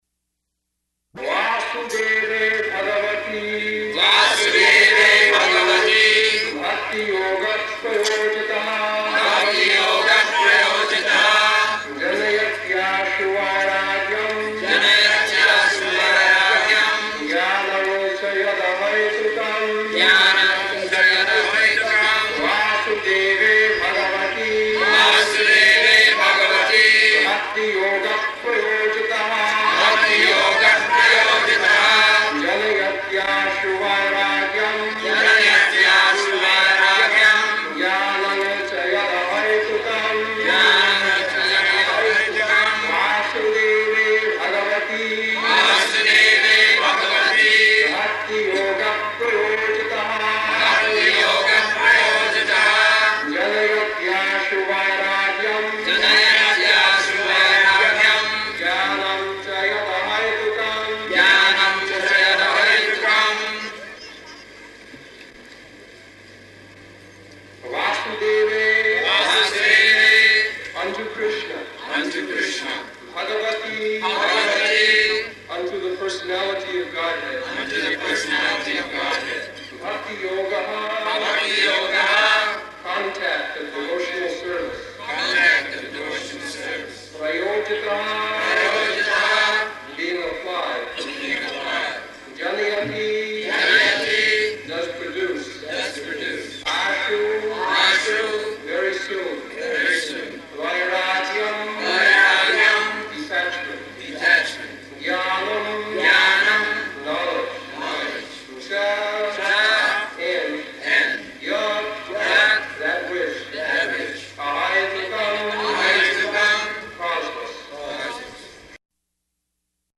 April 21st 1974 Location: Hyderabad Audio file